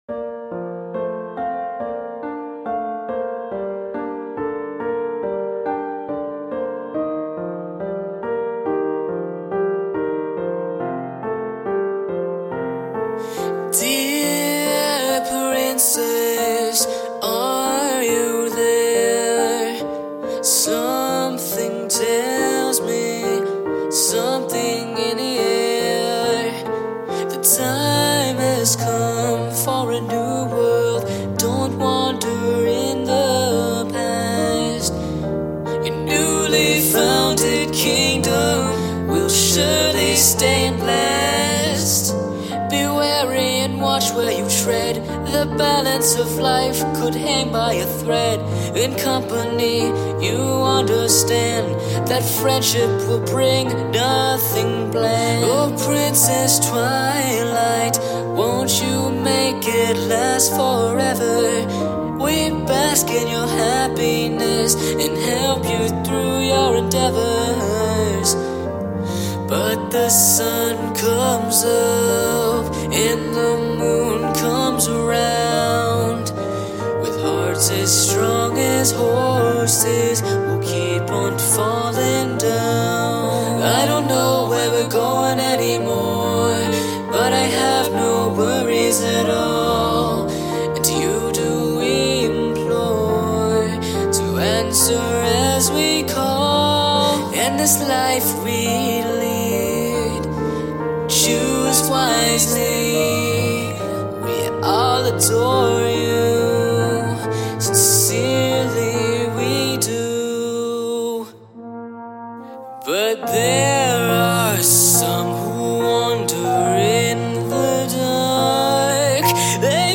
Tempos: 70, 120, 148, 150, 100, 128 Key: A# Major & Minor